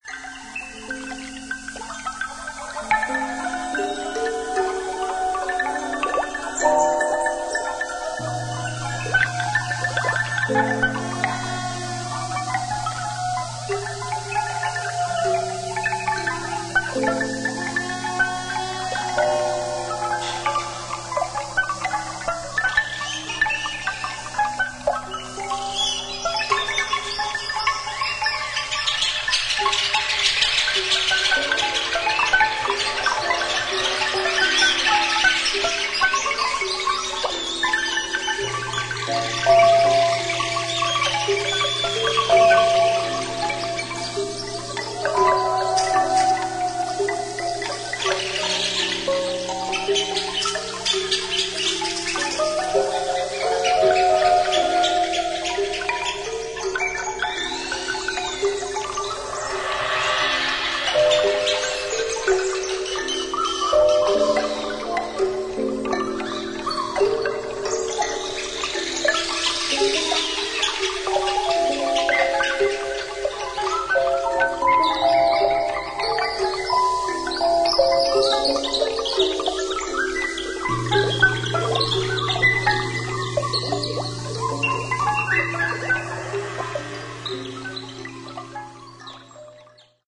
エキゾなチルアウト・ナンバー